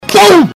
boom